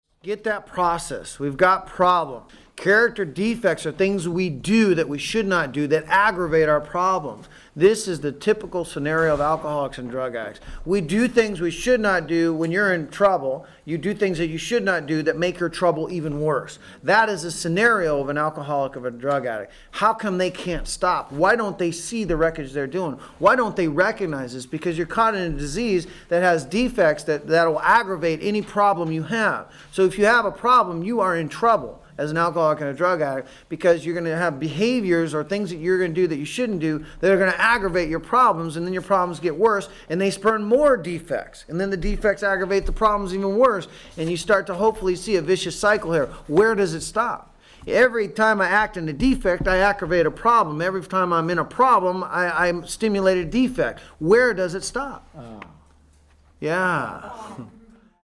This audio archive is a compilation of many years of lecturing. The spiritual and science used by Thought Life Connection were borrowed from religion, science, and medicine.
Within the lectures, you will hear people ask questions about why am I where I am, how can I get to a better place and what is blocking me.